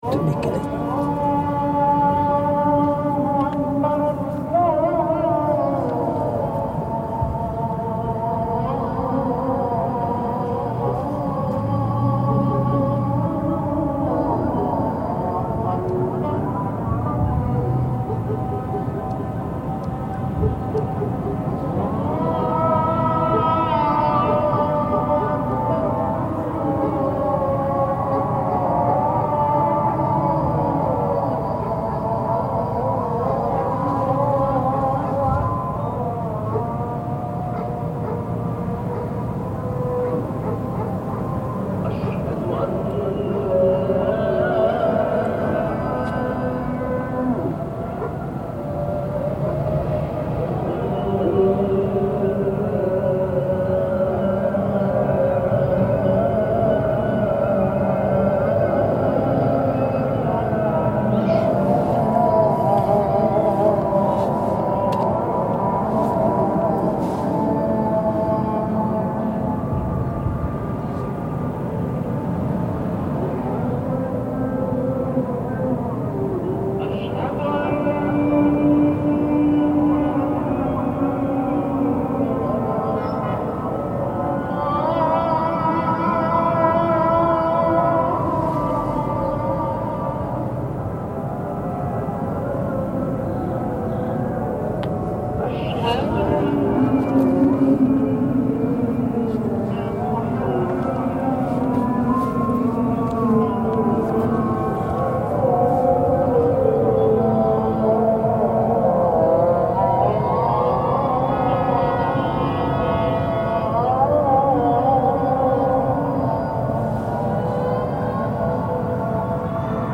Recordings of ambience from Aṭ-Ṭūr (Mount of Olives) overlooking the Old City of Jerusalem. The Al Aqsa temple-mount in the distance, the jewish graveyard close by, site of important historic and contemporary events.
This soundscape-composition is part of the HEYR project, presenting 3-dimensional soundscapes from special locations, connected to special events.